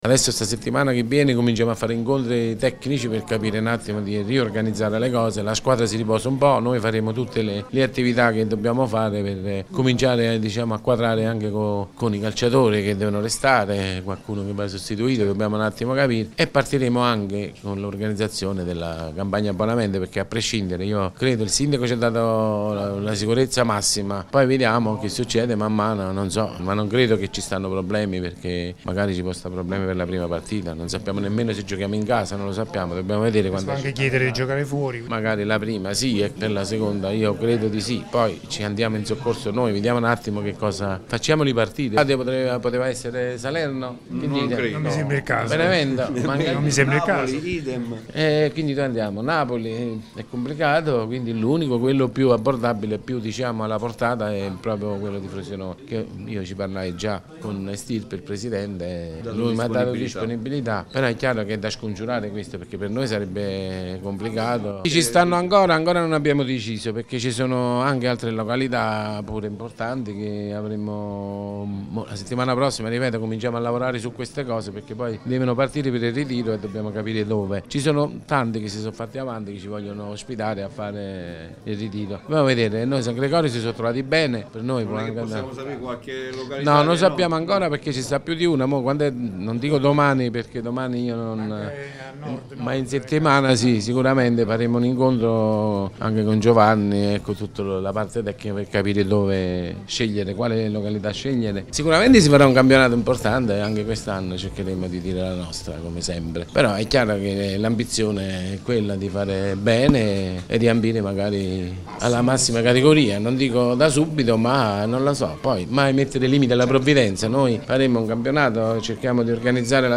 A margine dell’evento organizzato dal club “Adriano Lombardi” di Montella (AV),